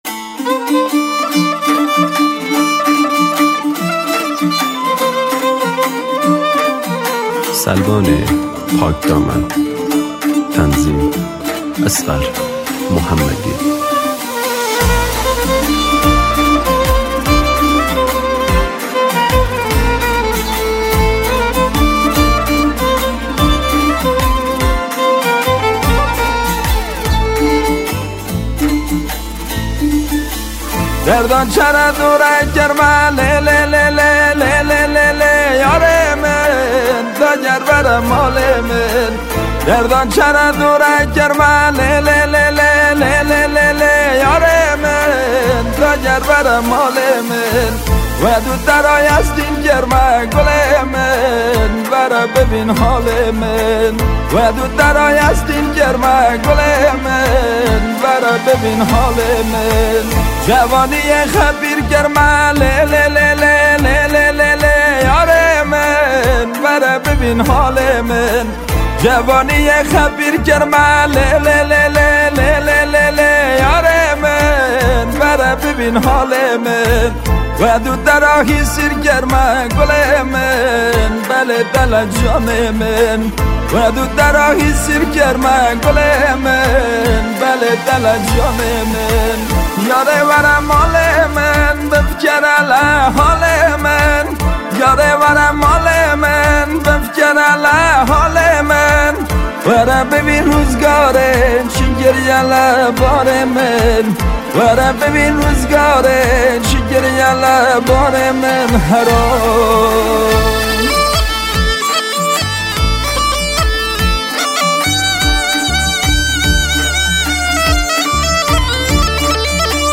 کرمانجی